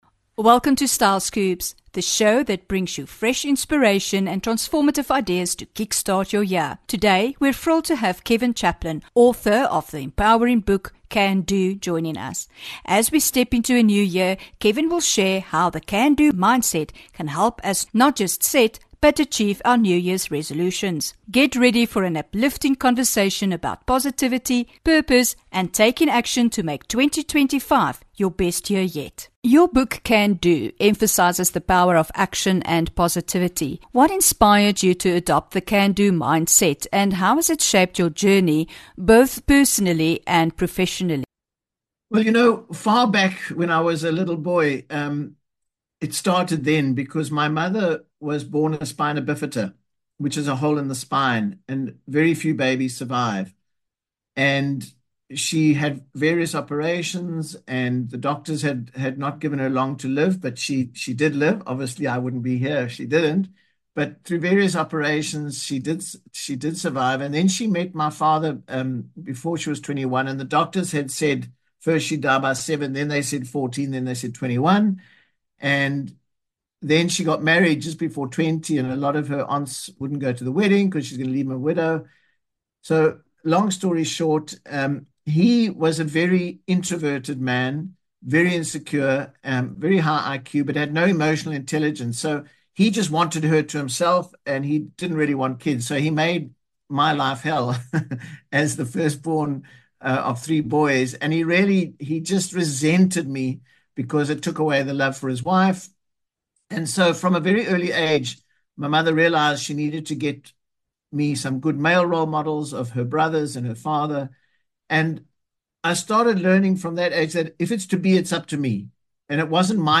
Get ready for an uplifting conversation about positivity, purpose, and taking action to make 2025 your best year yet